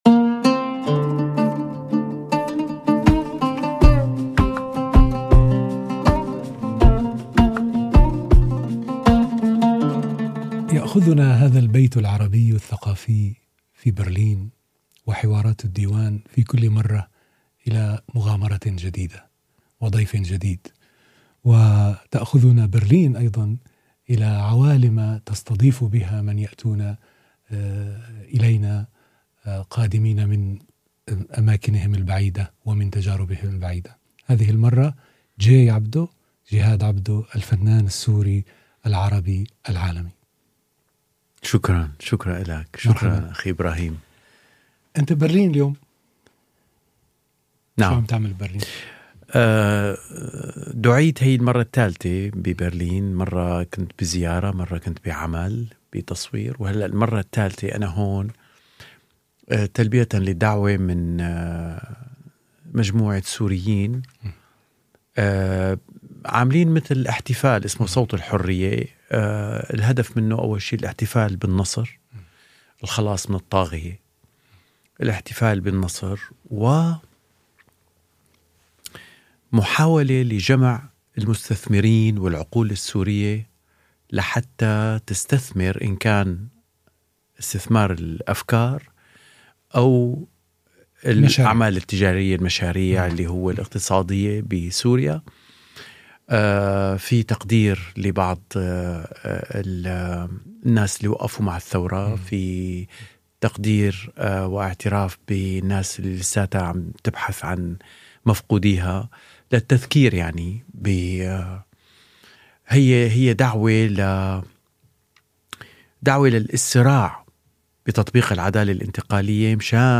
[AR] Im Gespräch mit Jihad Jay Abdo حوار الديوان مع جهاد عبده ~ DIVAN Podcasts Podcast